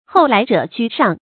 后来者居上 hòu lái zhě jū shàng
后来者居上发音